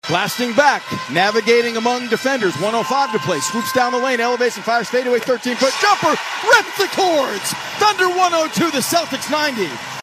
PBP- Thunder 102-90-Gilgeous-Alexander  Jumper.MP3